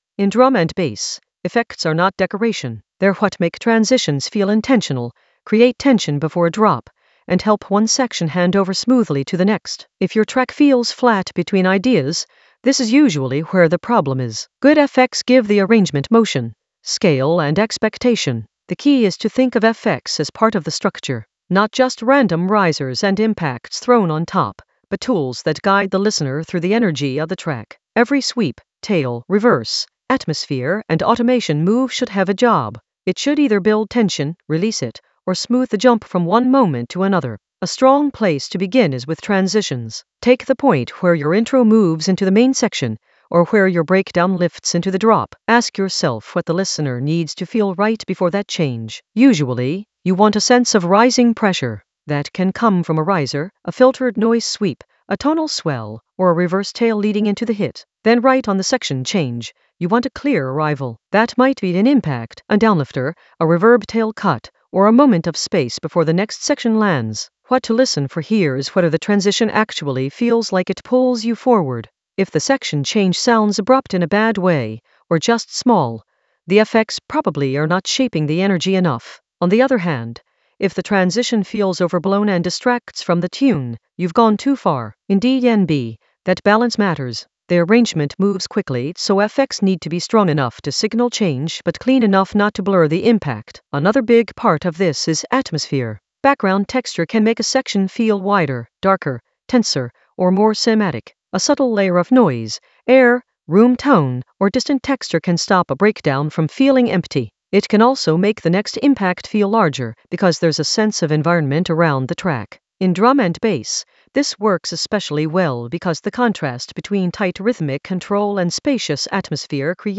An AI-generated beginner Ableton lesson focused on smooth reece bassline in ableton in the Basslines area of drum and bass production.
Narrated lesson audio
The voice track includes the tutorial plus extra teacher commentary.